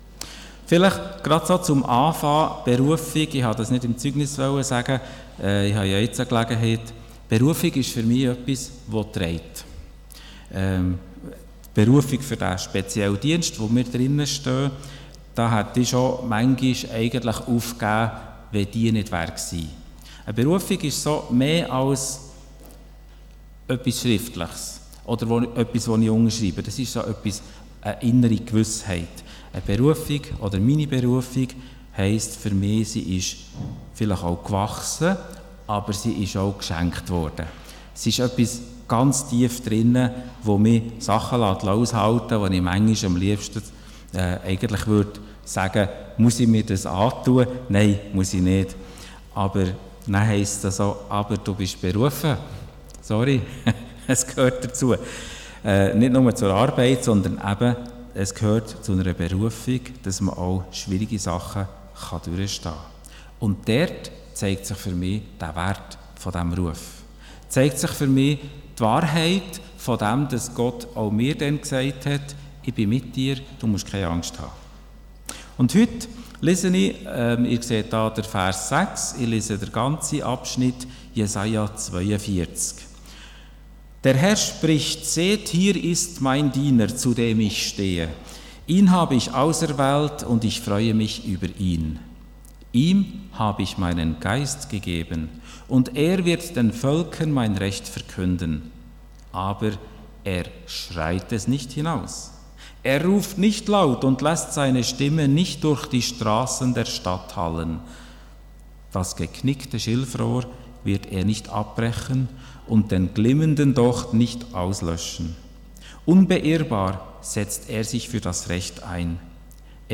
Von Serien: "Diverse Predigten"